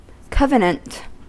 Ääntäminen
IPA : /ˈkʌv.ən.ənt/ US : IPA : [ˈkʌv.ən.ənt]